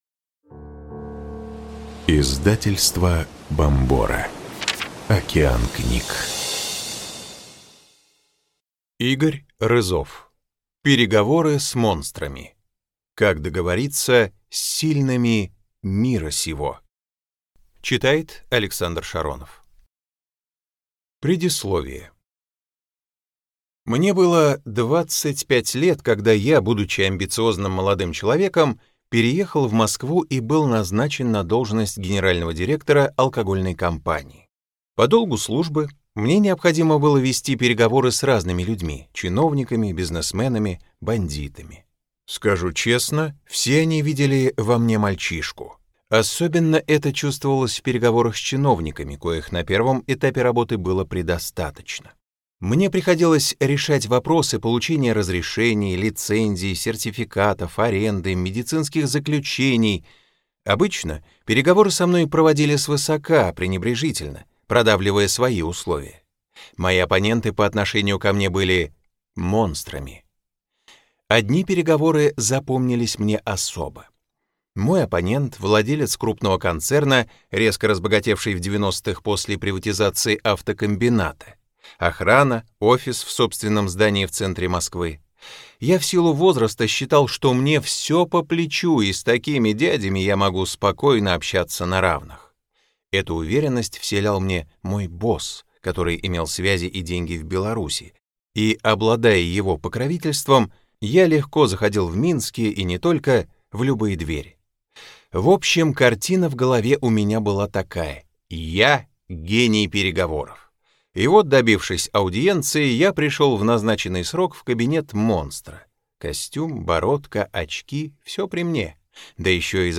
Аудиокнига Переговоры с монстрами. Как договориться с сильными мира сего | Библиотека аудиокниг